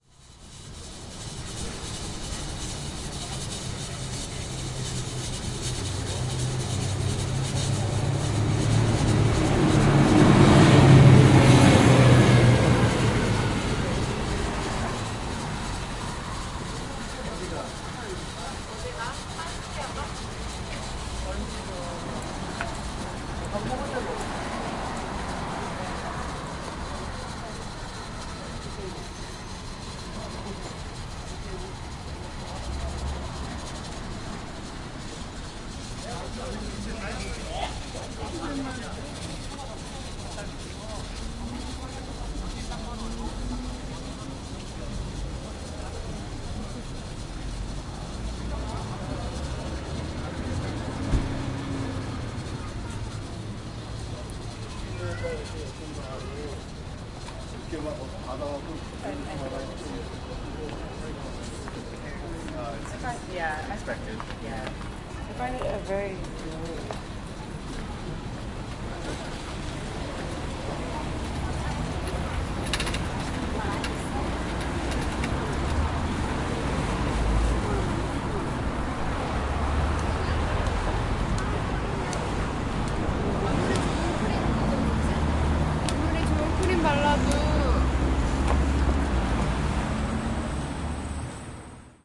汉城 " 0185 交通和扬声器
描述：交通和街上的扬声器发出的声音。
标签： 现场录音 语音 韩语 交通 首尔 韩国
声道立体声